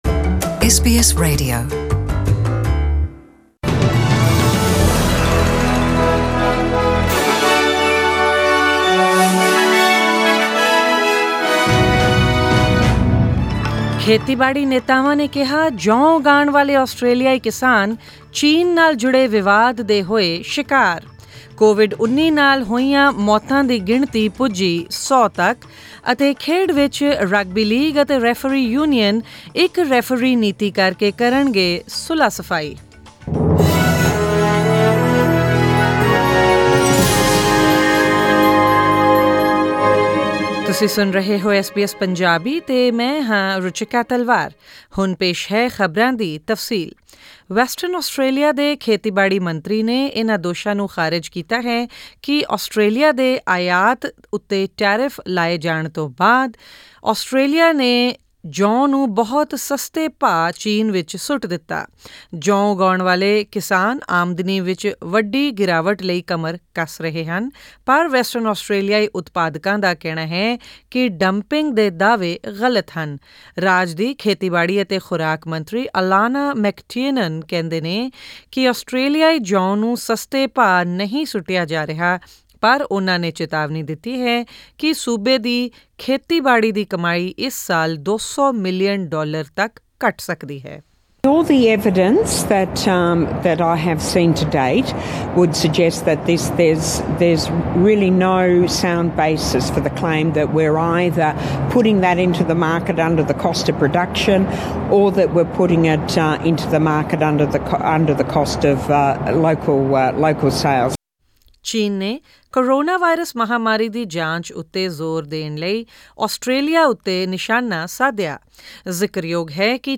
Australian News in Punjabi: 19 May 2020